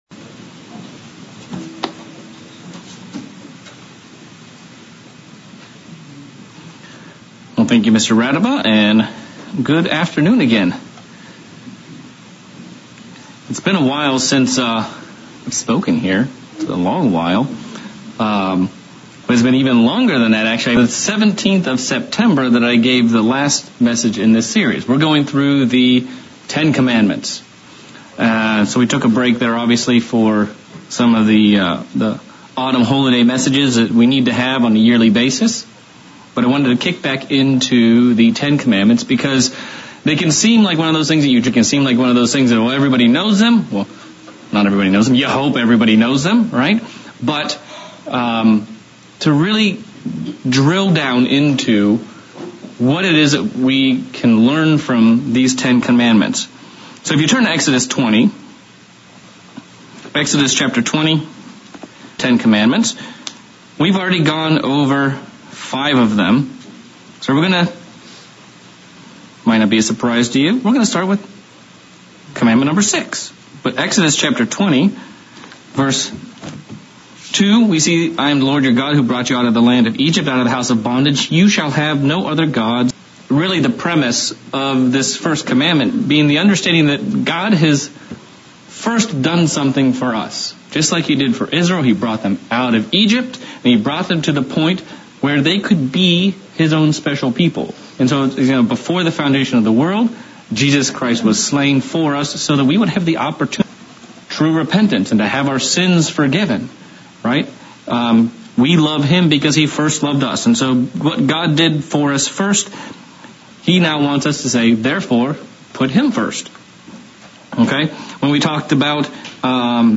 Sermon looking at the 6th commandment. What is the difference between murder and accidental killing? How did Christ expand on these and what should we do to put away the evil from our lives?